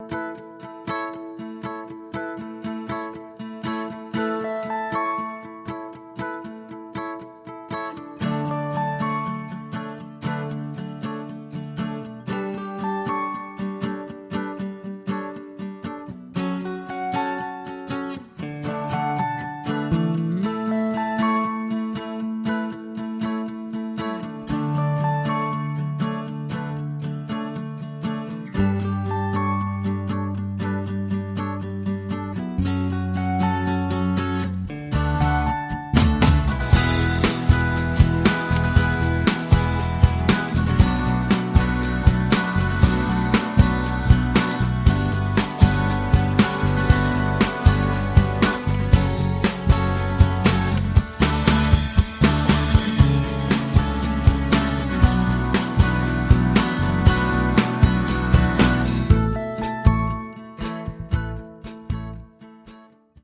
My Junk (no Vocals)